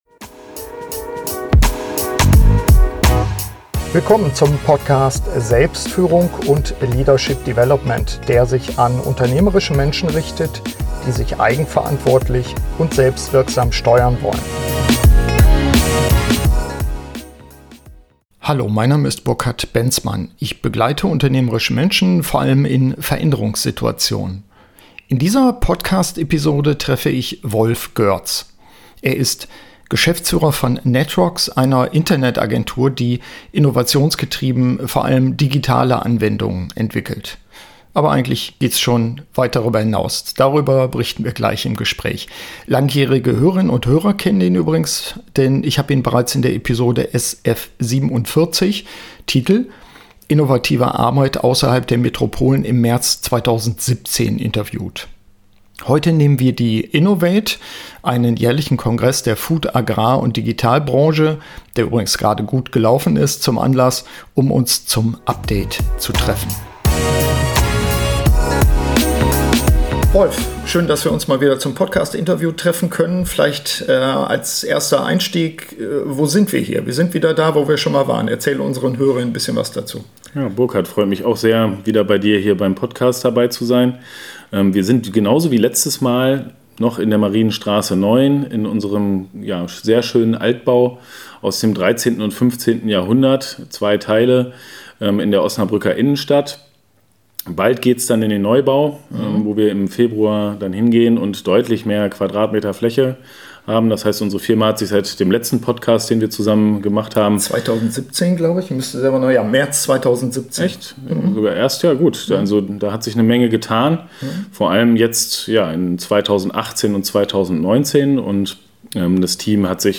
SF116 innovate! - Update-Interview